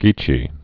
(gēchē)